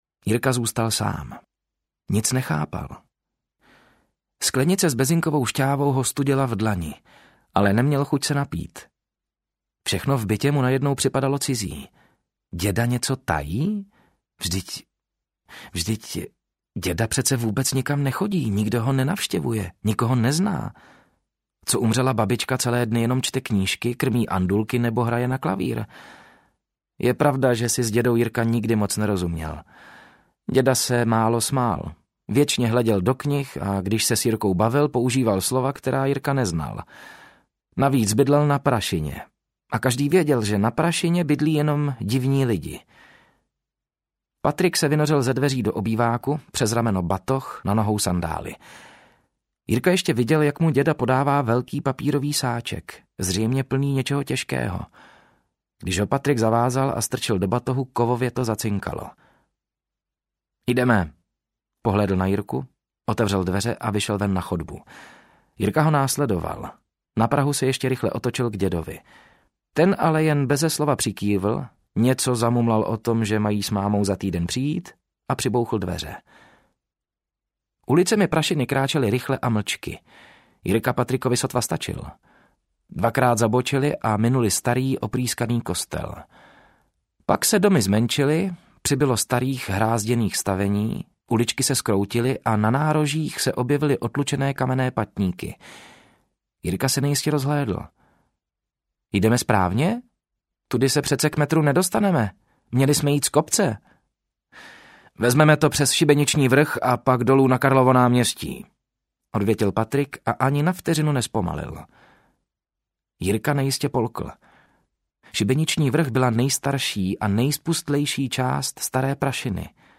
Prašina audiokniha
Ukázka z knihy
prasina-audiokniha